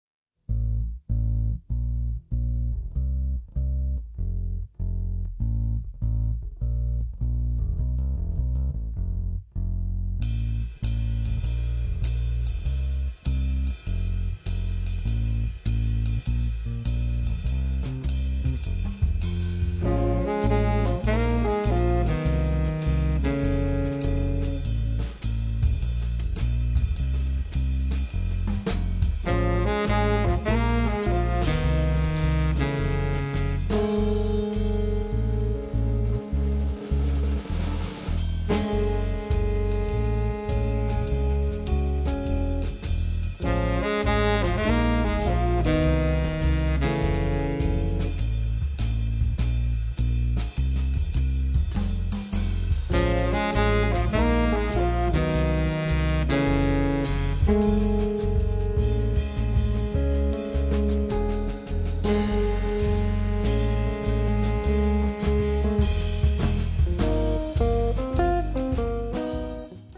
Chitarra
Sax Baritono
Contrabbasso e Basso Elettrico
Batteria